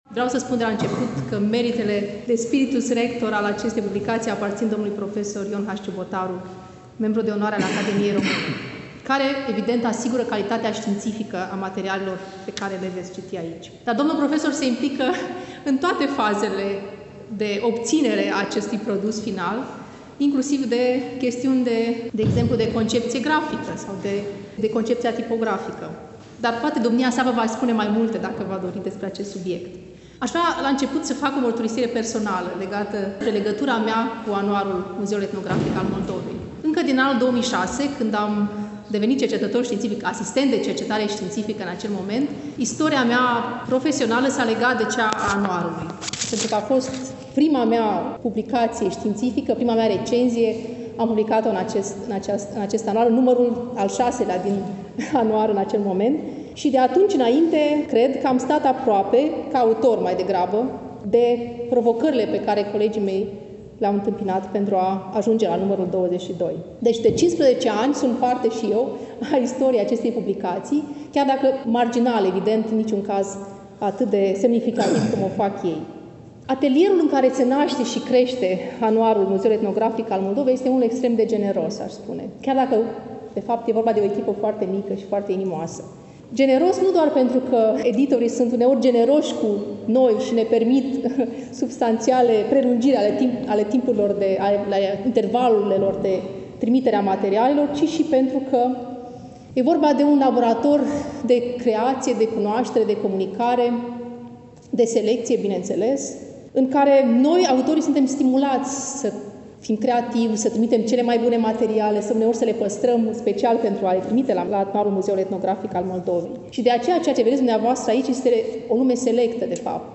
Stimați prieteni, vă reamintim că relatăm de la prezentarea „Anuarului Muzeului Etnografic al Moldovei”, Nr. XXII, lansat, nu demult, la Iași, în Sala „Petru Caraman” din incinta Muzeului Etnografic al Moldovei, Palatul Culturii.